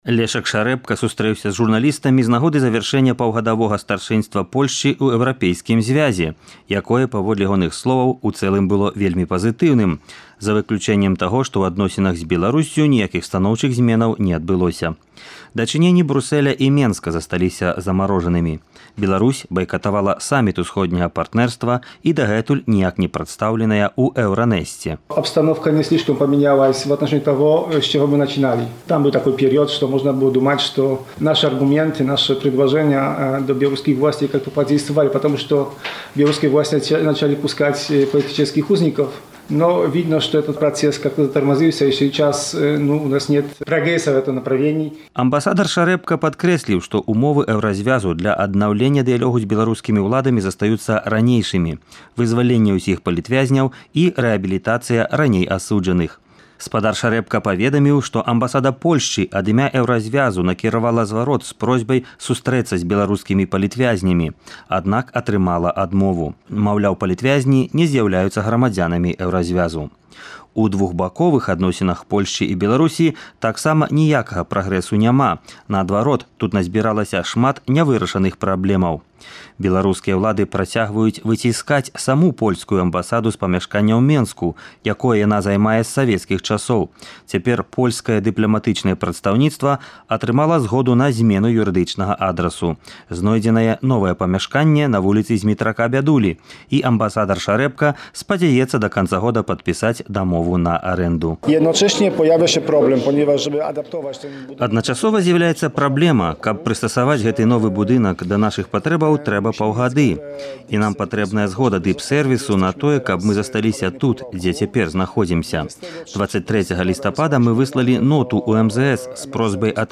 Выніковы рэпартаж